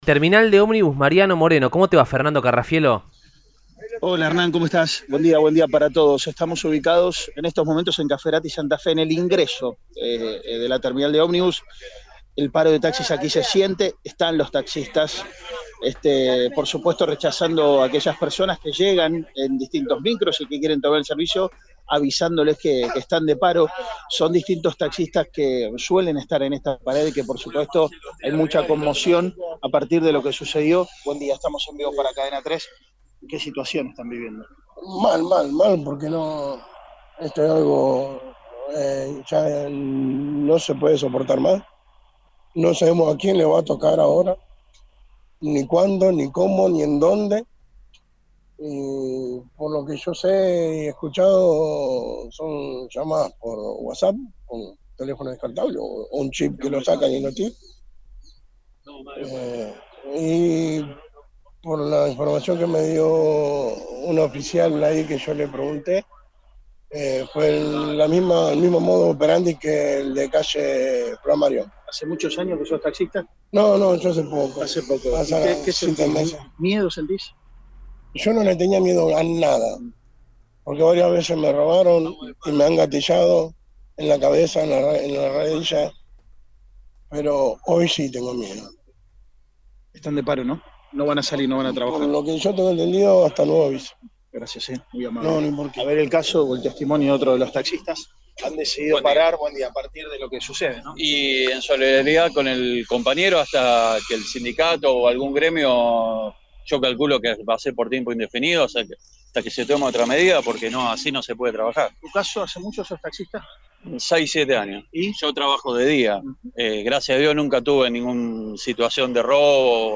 En ese marco, taxistas se manifestaban en distintos puntos de la ciudad, como la Sede de Gobierno y la Terminal de Ómnibus Mariano Moreno.